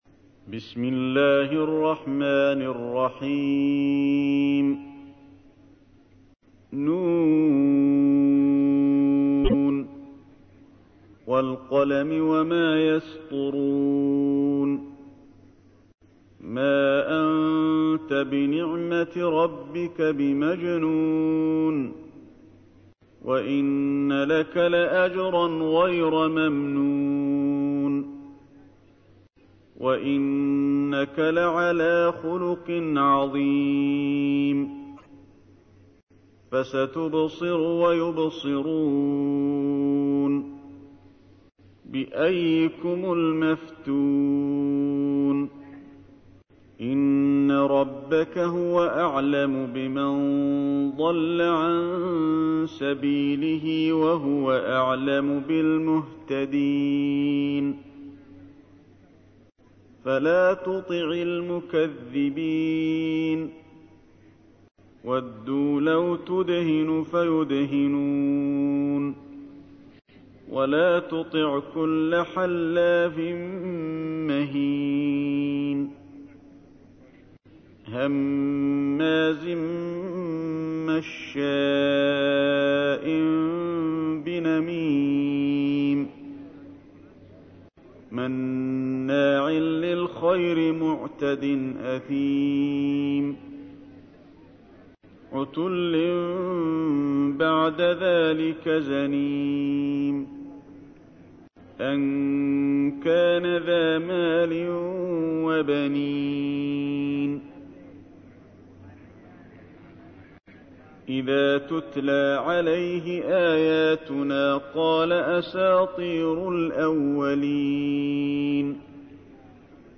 تحميل : 68. سورة القلم / القارئ علي الحذيفي / القرآن الكريم / موقع يا حسين